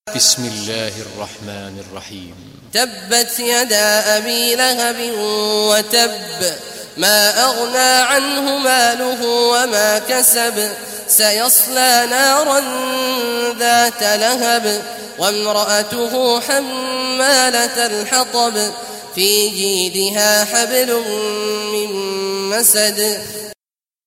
Surah Al-Masad Recitation by Sheikh Awad Juhany
Surah Al-Masad, listen or play online mp3 tilawat / recitation of Surah Al-Masad in the voice of Imam Sheikh Abdullah Awad al Juhany.